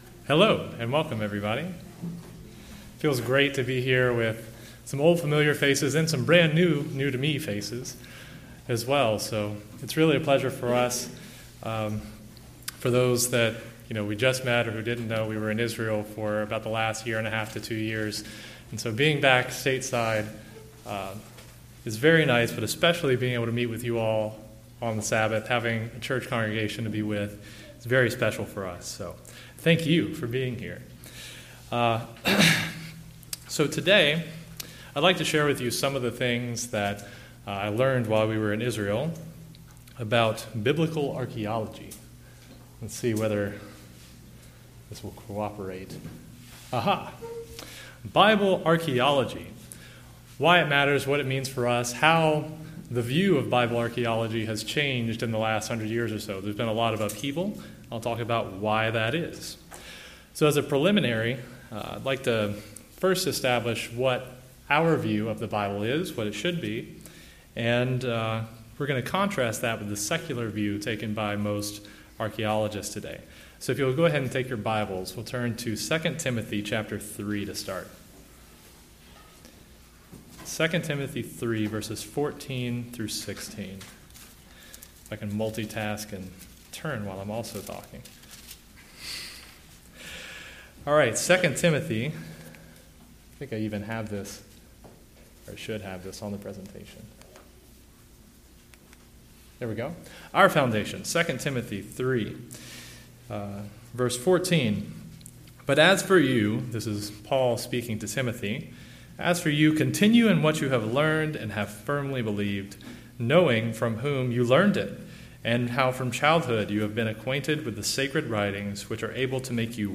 Given in Raleigh, NC